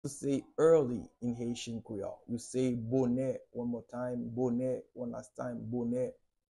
How to say “Early” in Haitian Creole – “Bonè” pronunciation by a native Haitian Teacher
“Bonè” Pronunciation in Haitian Creole by a native Haitian can be heard in the audio here or in the video below:
How-to-say-Early-in-Haitian-Creole-–-Bone-pronunciation-by-a-native-Haitian-Teacher.mp3